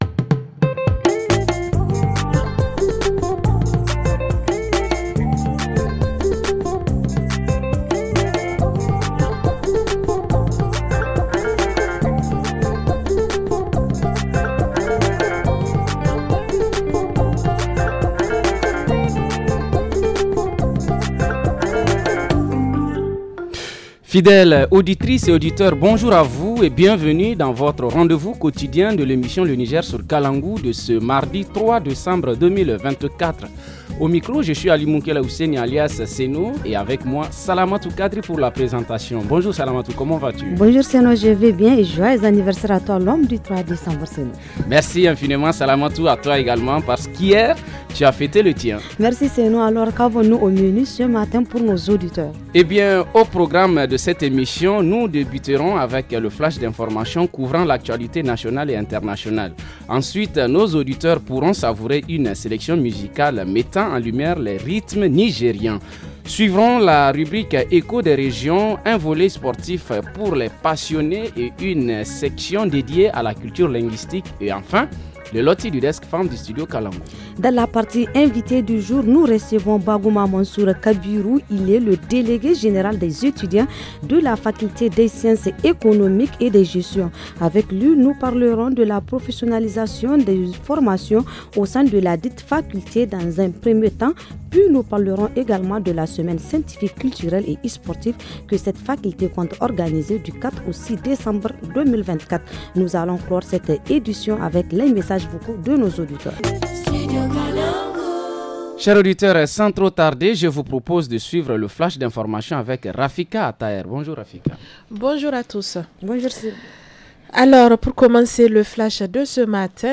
Dans la partie rubrique hebdomadaire santé, la prise en charge des malnutris au CSI de Matameye. En reportage région, la santé animale à Diffa.